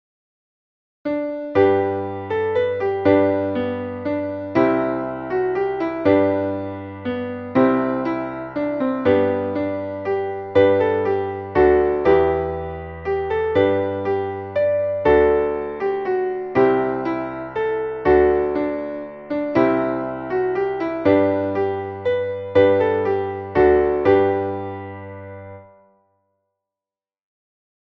Traditionelles Frühlingslied